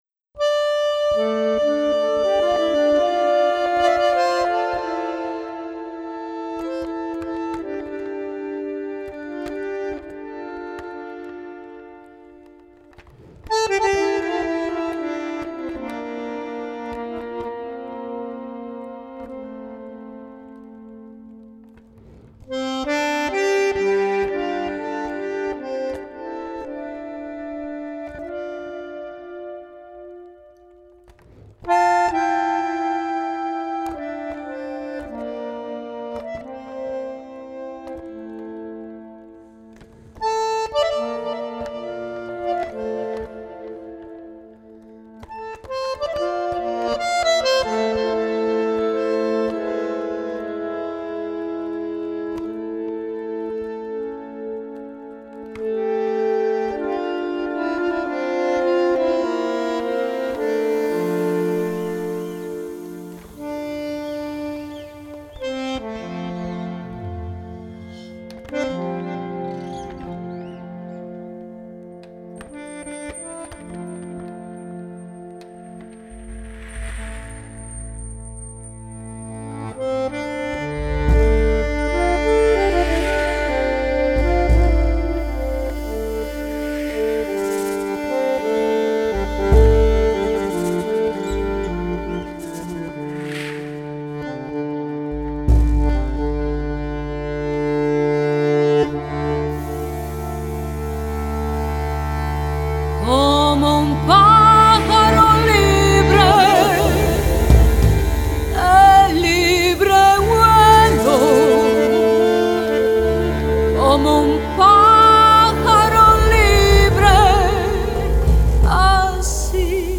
bandoneon
vocalist